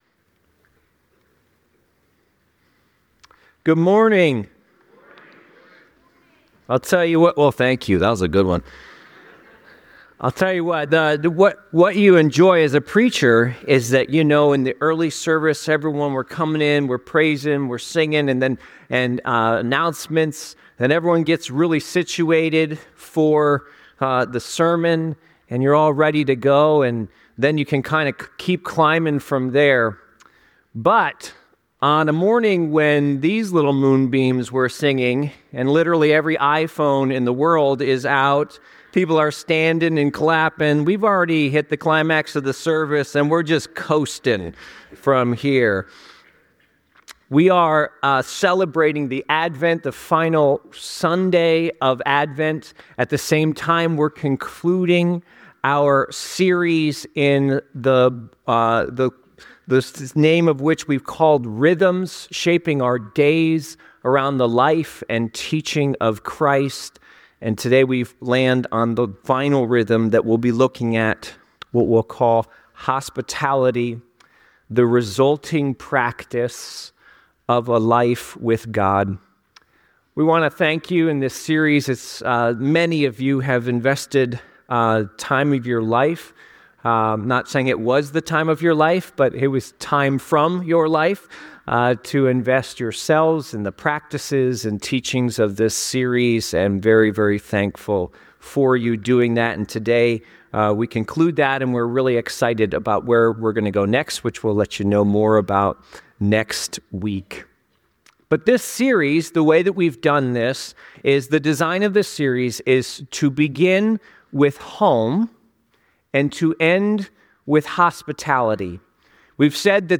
This sermon was delivered on the final Sunday of Advent, focusing on hospitality as the concluding theme of the 'Rhythms' series.